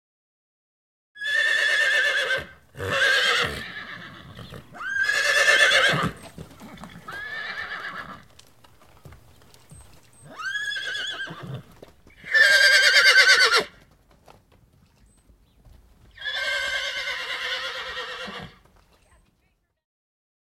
Whiney | Sneak On The Lot
Horses, Several; Whinnies And Hoof Steps, Various Perspectives.